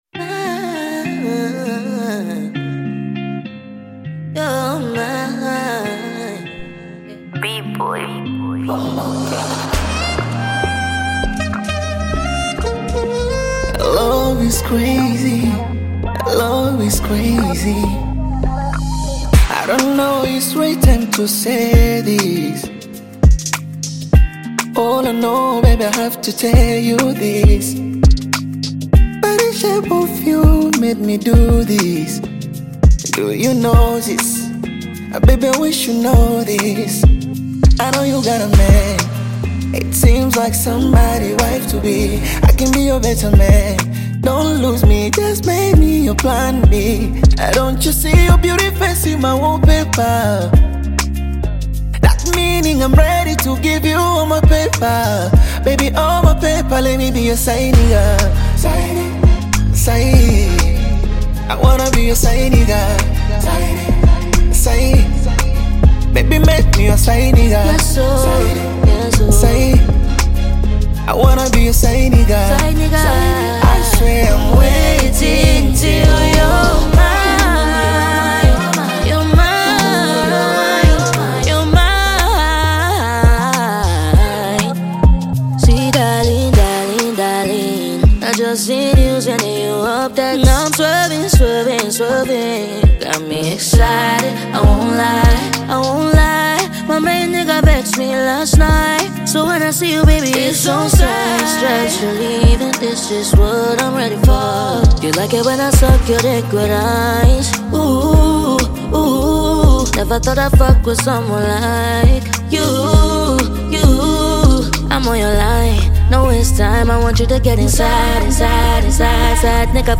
Bongo Flava music track
Tanzanian Bongo Flava